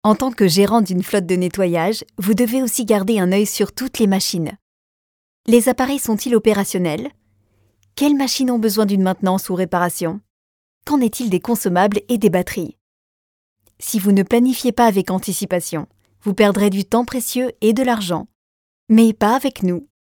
Stem
Natuurlijk, Diep, Speels, Zacht, Commercieel
E-learning